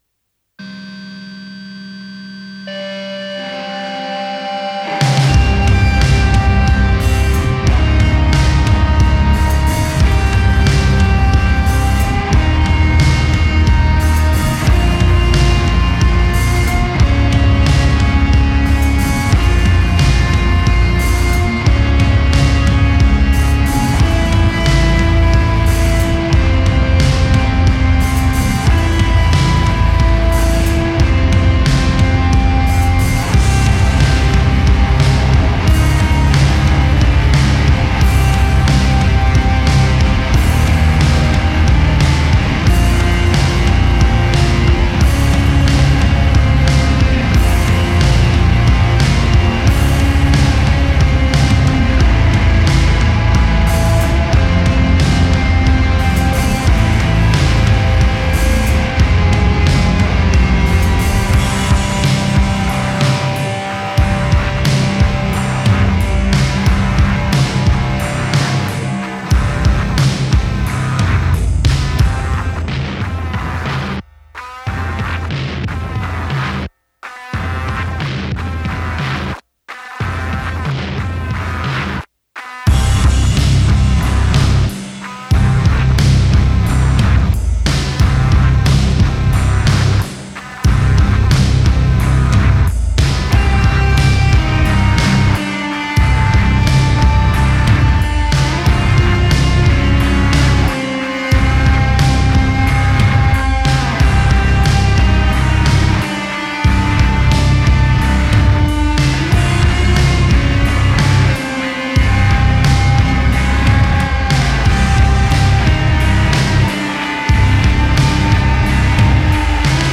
sludge